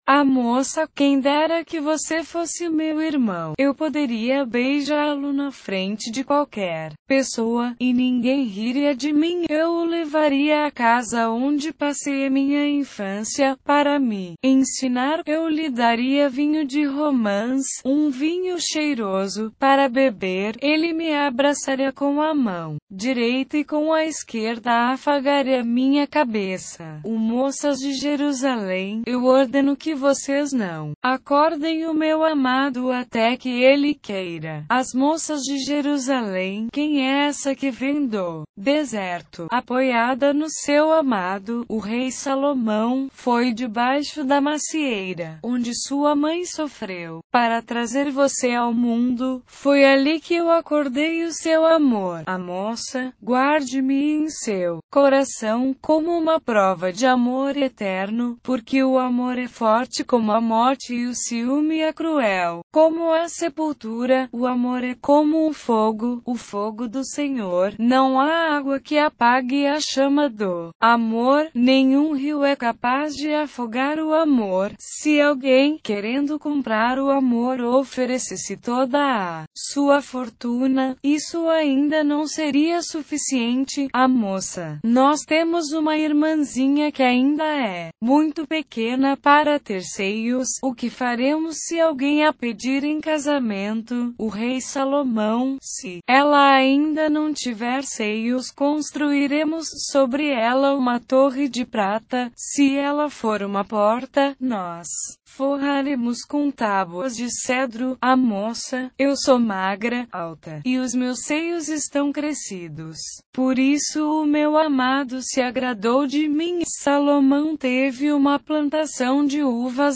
Leitura na versão Bíblia Viva - Portugués